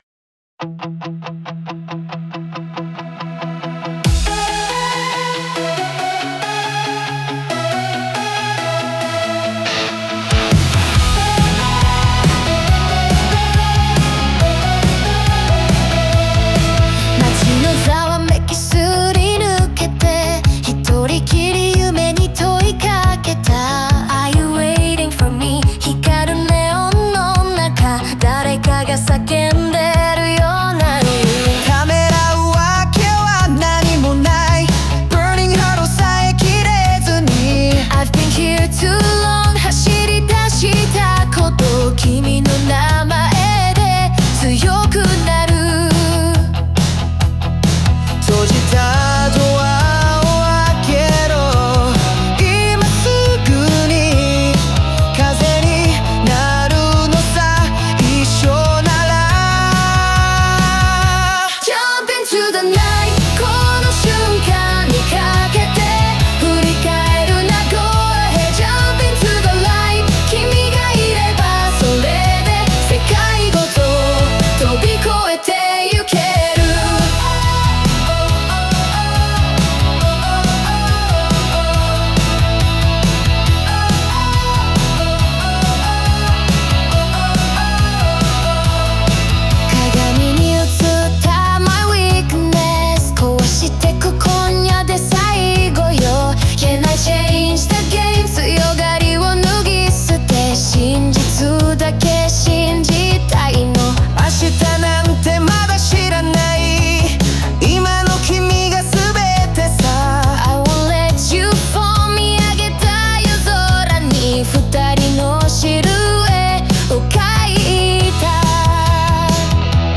オリジナル曲♪